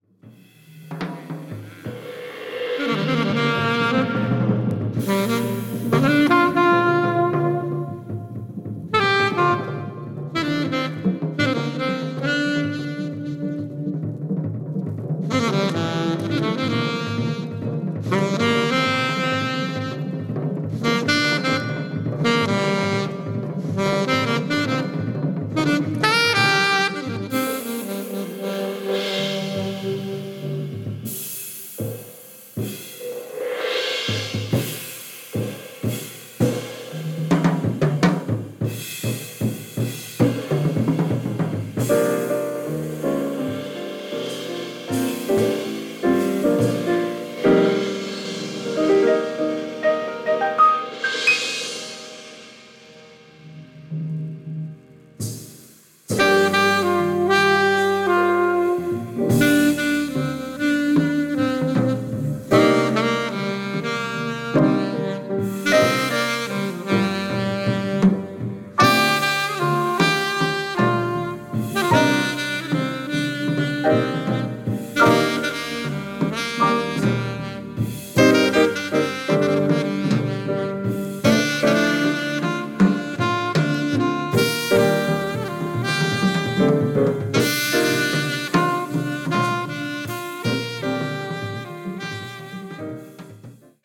Van Gelder Recording Studioに集まった4人、ステキです！！！